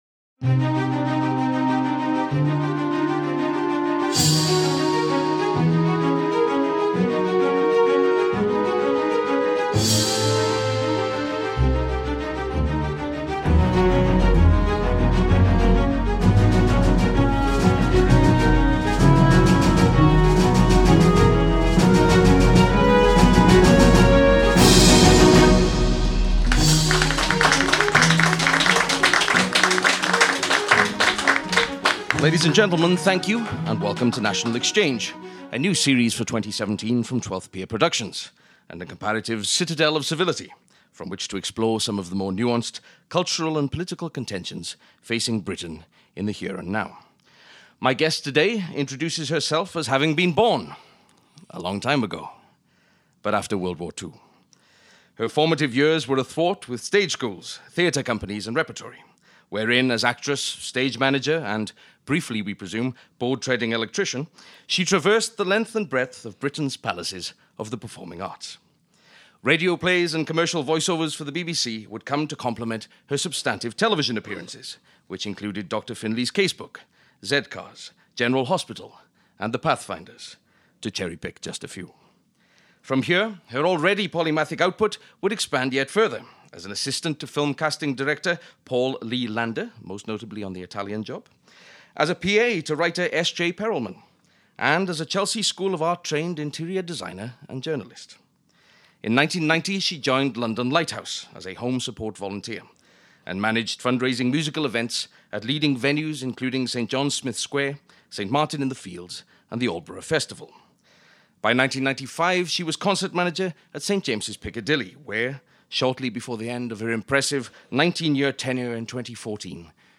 NATIONAL EXCHANGE Interviews before a live studio audience, exploring current matters of cultural and political contention across Britain.